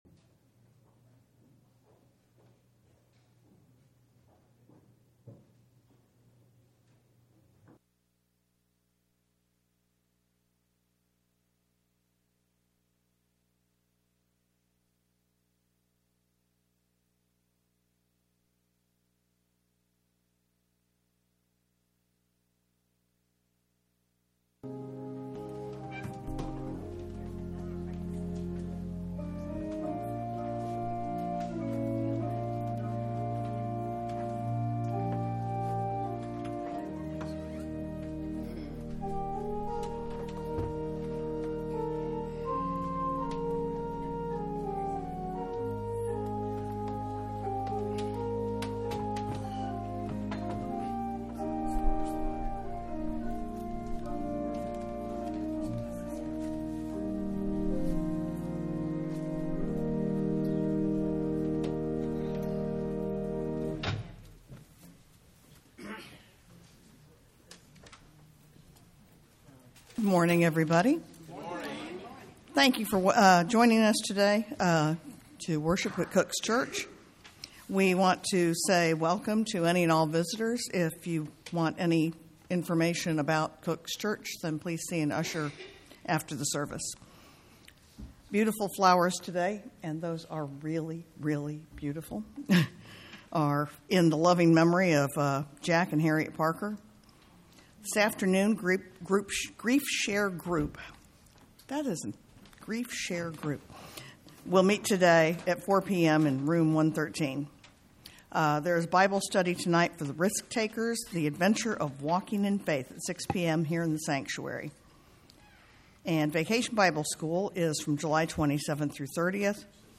Matthew 4:19 Service Type: Sunday Morning